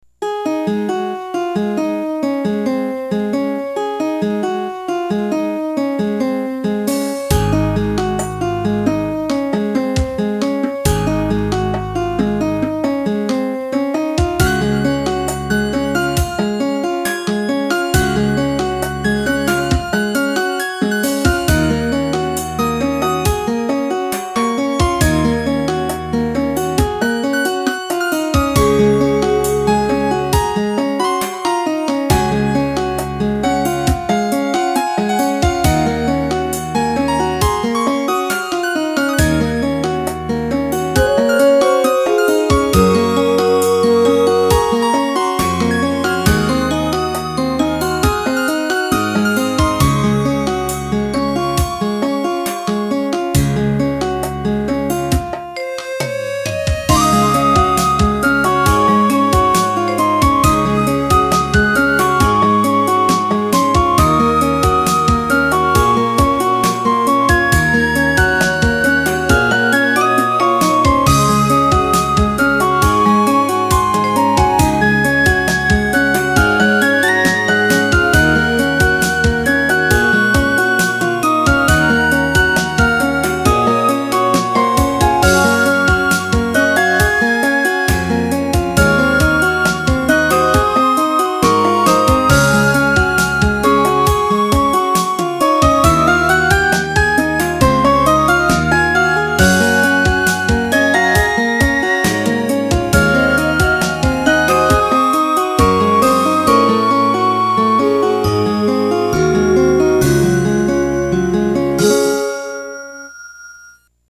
遥か上空にいる中、どこか優しげなフルートの音色が安心感を与えてくれます。